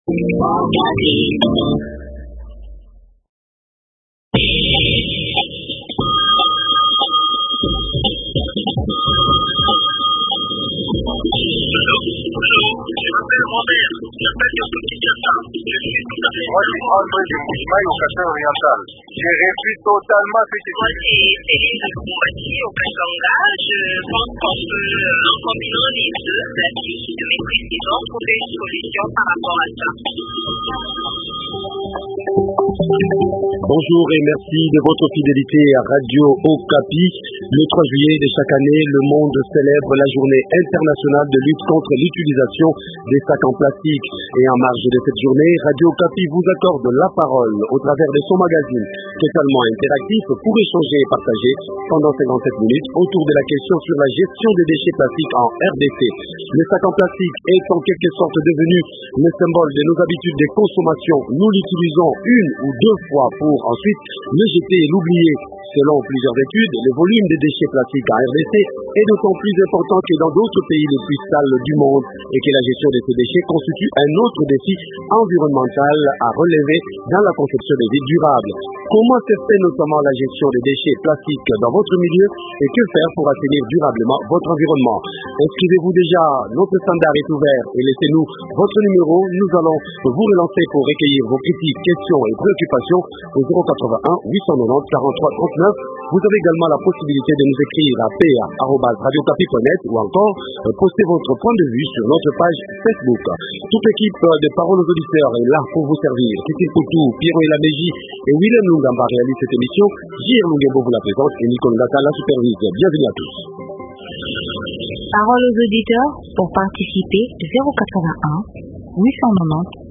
En marge de cette journée, Radio Okapi vous accorde la parole autour de la question sur la gestion des déchets plastiques en RDC.